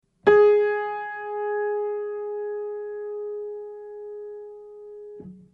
ランダムに異なるに音を鳴らし音感の特訓をします。
Gs.mp3